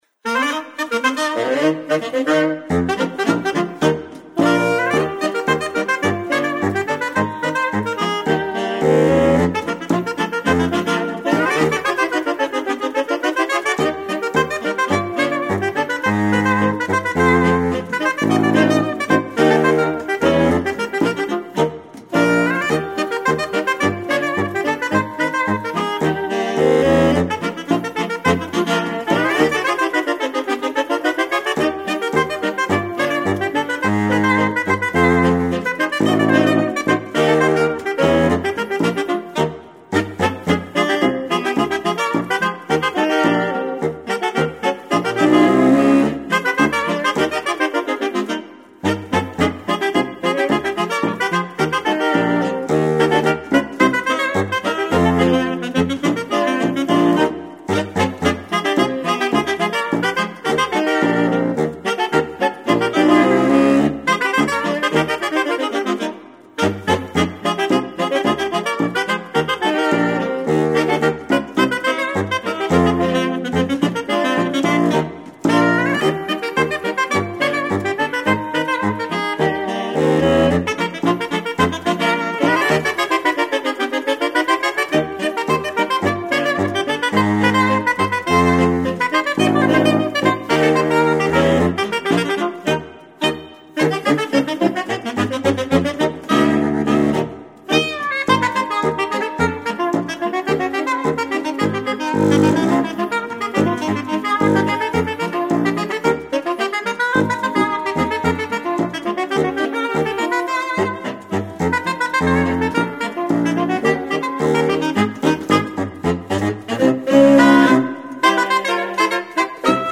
Voicing: Saxophone Sextet